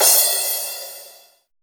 DIRT CRASH.wav